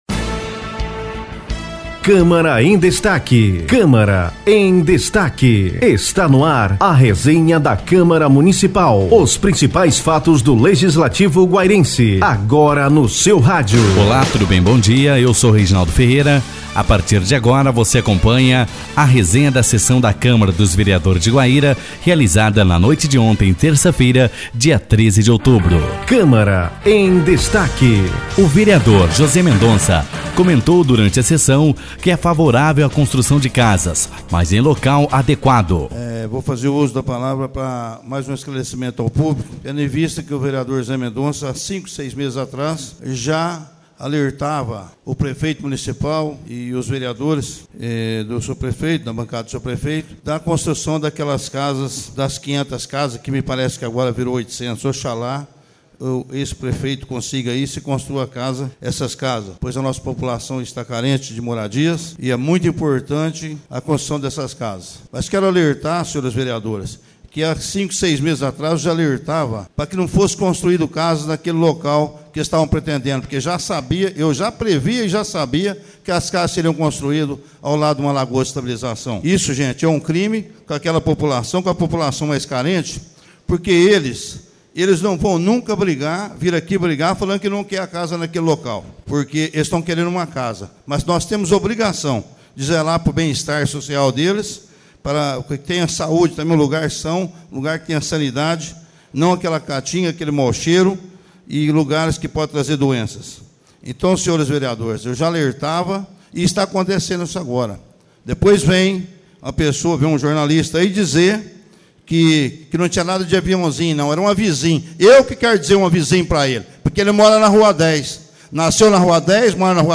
Na manhã de hoje, as rádios SEFE FM (98,7) e Cultura AM (1470 kHz) divulgaram a resenha da sessão.